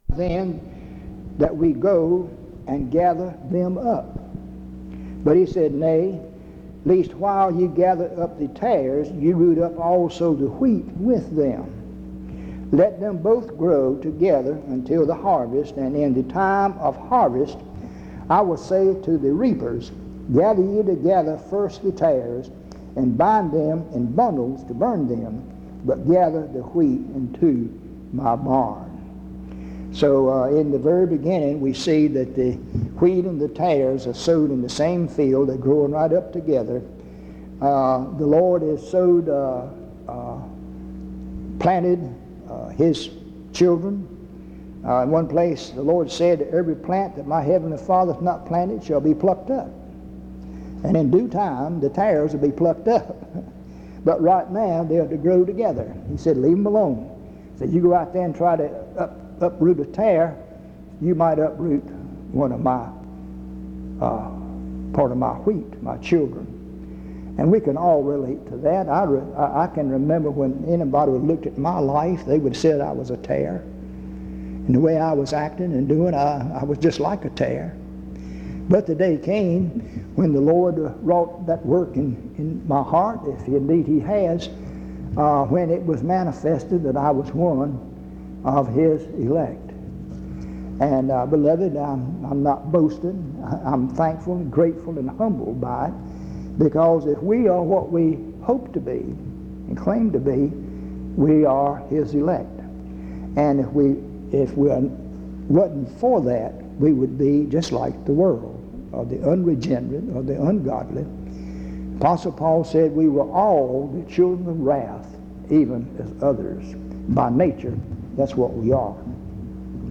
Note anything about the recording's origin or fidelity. En Collection: Reidsville/Lindsey Street Primitive Baptist Church audio recordings Miniatura Título Fecha de subida Visibilidad Acciones PBHLA-ACC.001_079-A-01.wav 2026-02-12 Descargar PBHLA-ACC.001_079-B-01.wav 2026-02-12 Descargar